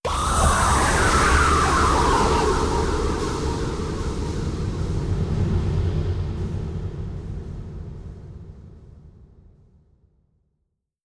Jumpscare_04.wav